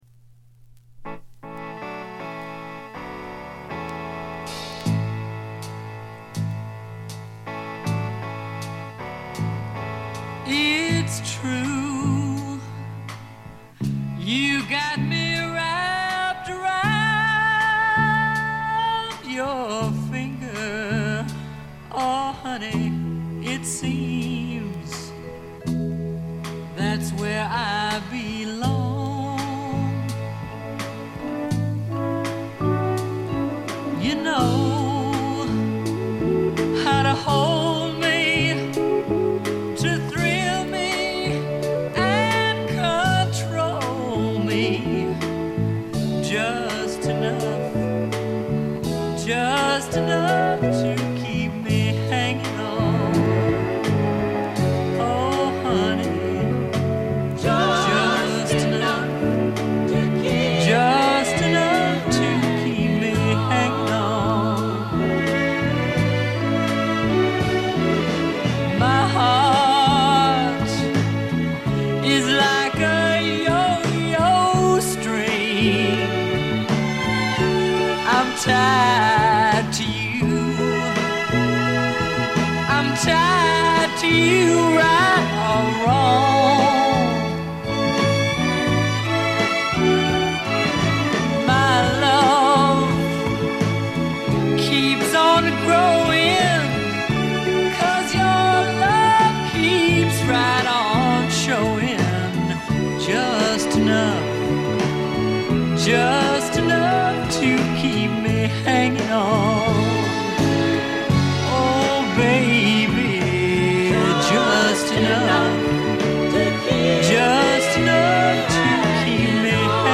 ほとんどノイズ感無し。
白ラベルのプロモ盤。モノ・プレス。
試聴曲は現品からの取り込み音源です。
Recorded At - Muscle Shoals Sound Studios